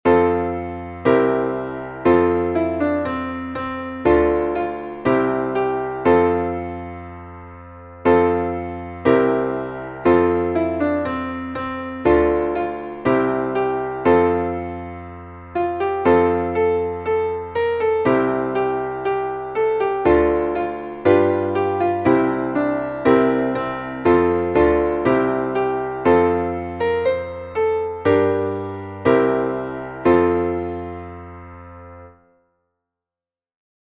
Traditionelles Abschieds-/ Wanderlied (19. Jahrhundert)